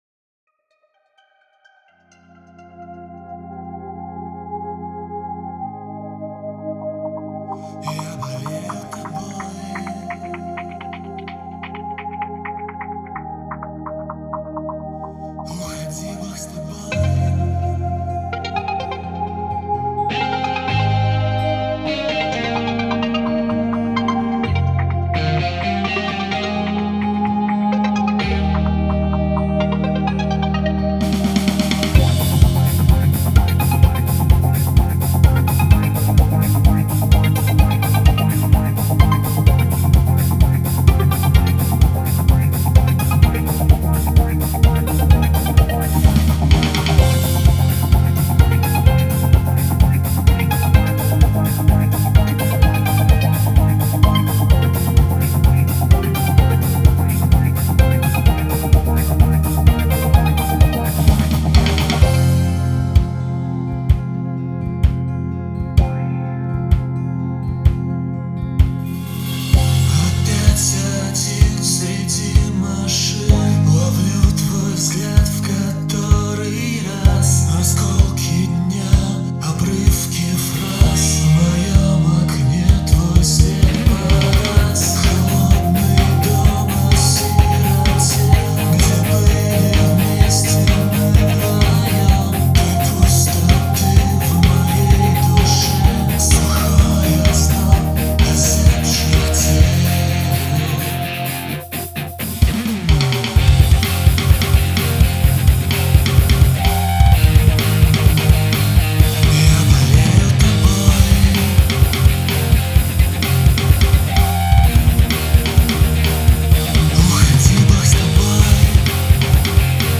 Стиль: Rock , metal